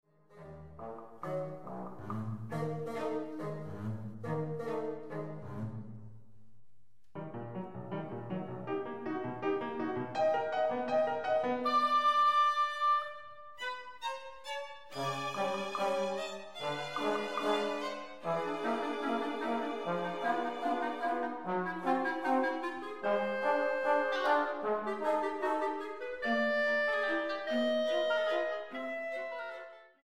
para conjunto de cámara